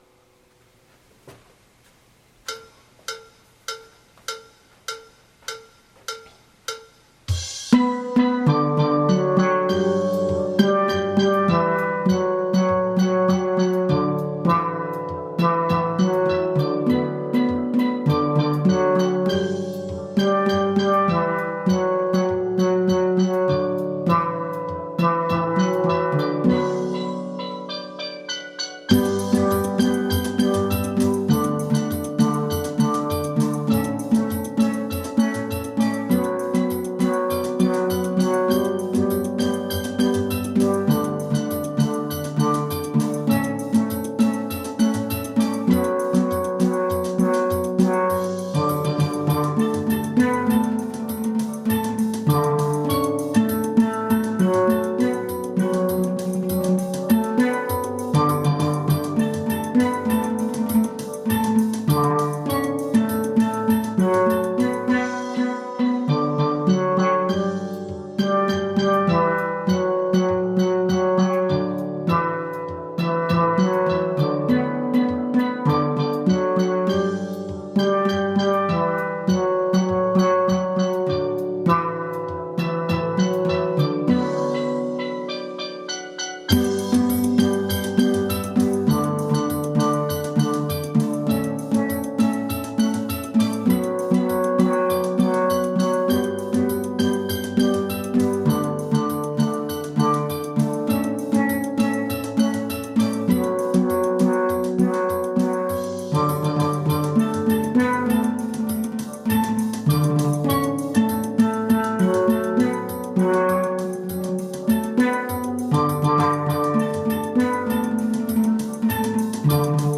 Kaducia Steel Band
demi refrain et fin ralentie.
Guitar Magic Vid .mp3